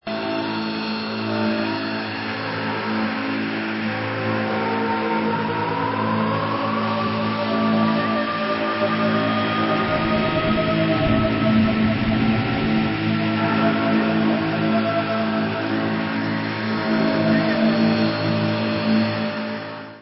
-ULTRA RARE MIND EXPANDING FUZZ FILLED GEMS! -